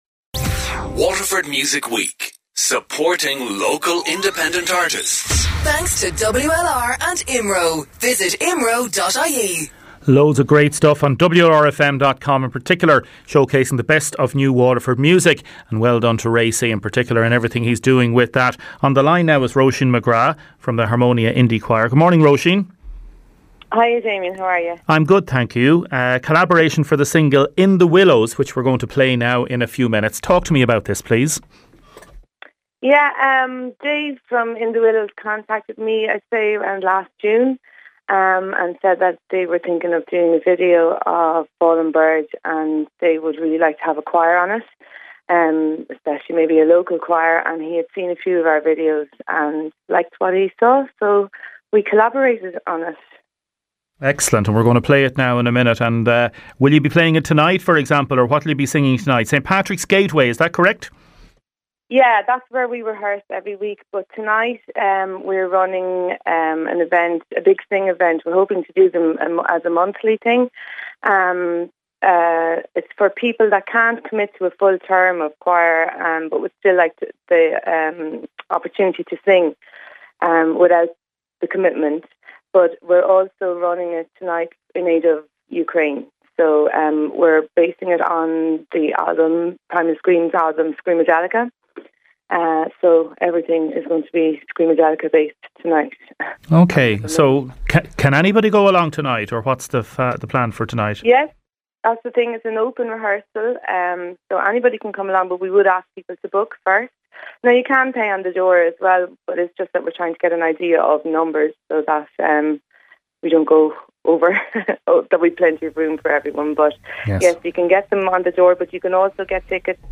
As part of the offering we had a number of guests in studio, and here’s your chance to hear them back: